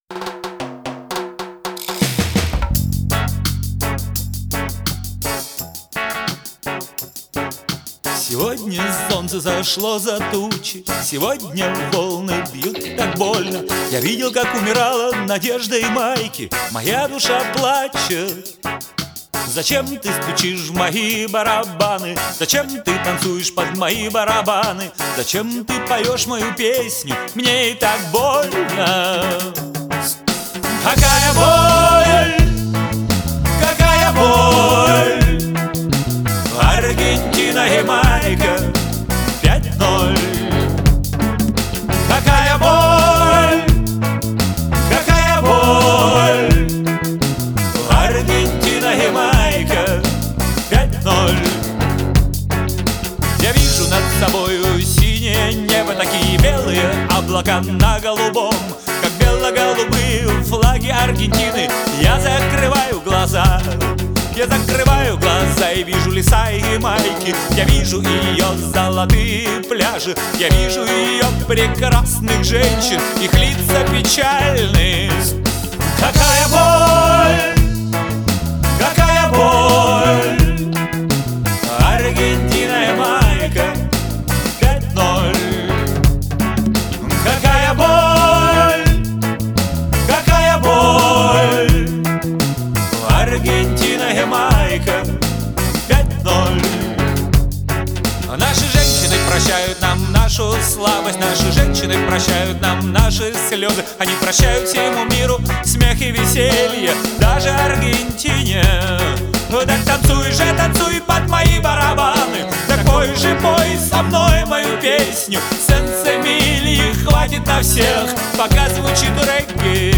Жанр: Rock